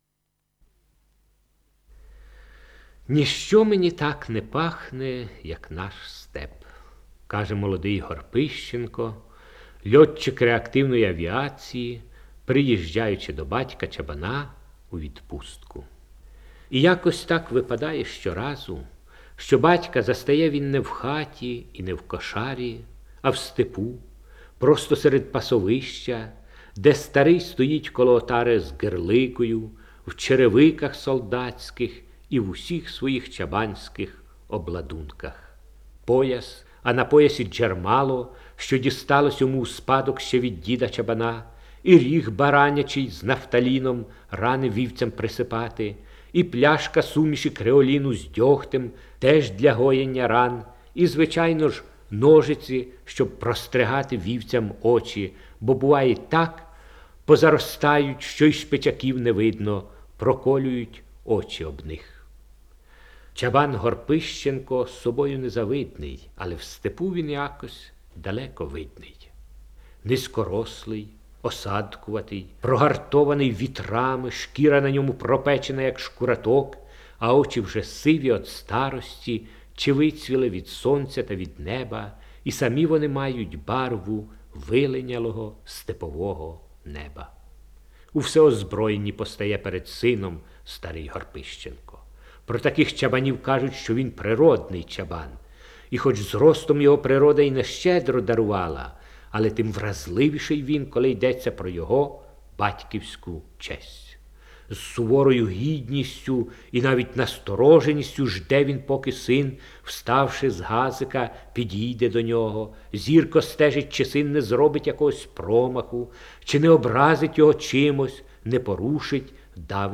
Index of /storage/Oles_Gonchar/Записи голосу Гончара